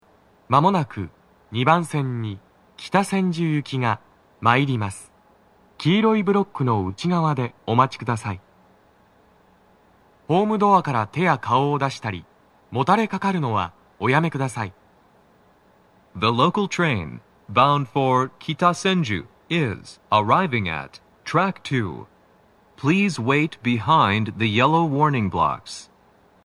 スピーカー種類 TOA天井型
鳴動は、やや遅めです。
男声